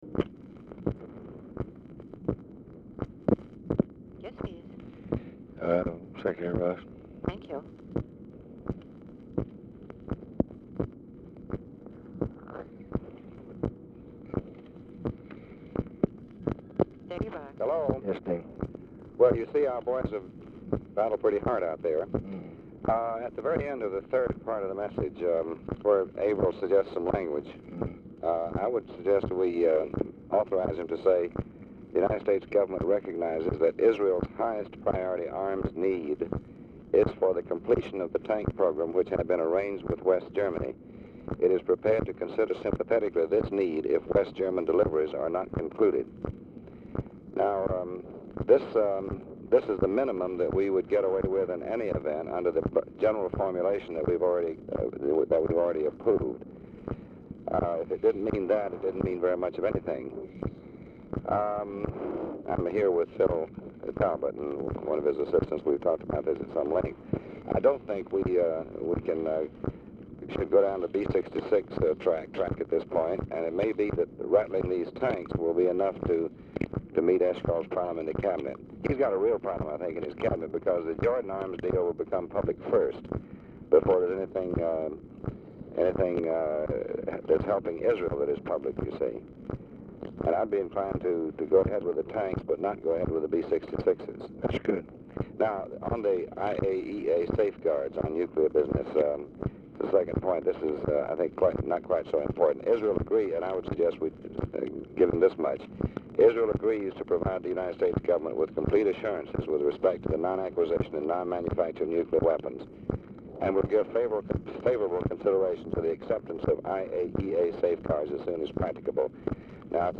Telephone conversation # 6897, sound recording, LBJ and DEAN RUSK, 2/27/1965, 11:37PM | Discover LBJ
Format Dictation belt
Location Of Speaker 1 Mansion, White House, Washington, DC
Specific Item Type Telephone conversation Subject Arms Control And Disarmament Communist Countries Defense Diplomacy Foreign Aid Middle East Ussr And Eastern Europe Western Europe